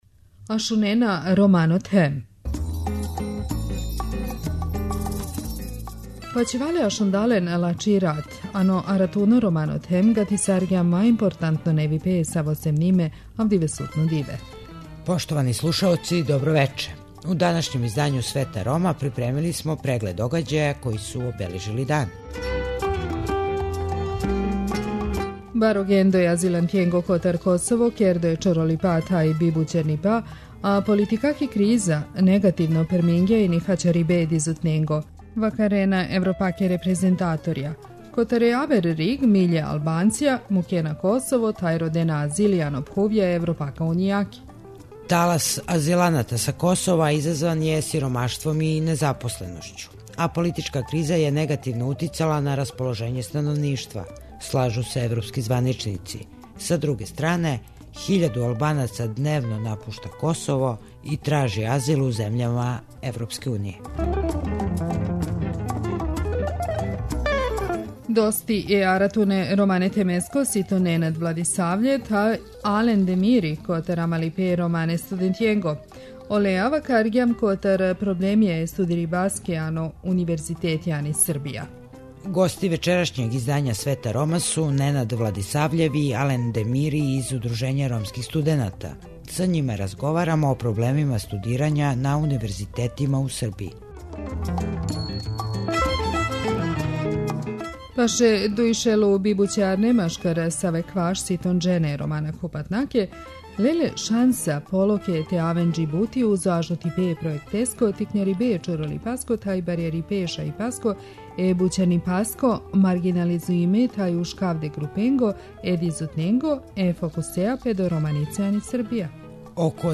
Кујтим Пачаку, ромски посланик у Скупштини Косова је гост емисије и са њим разговарамо због чега грађани Косова све више траже азил у земљама Уније.